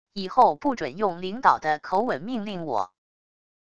以后不准用领导的口吻命令我wav音频生成系统WAV Audio Player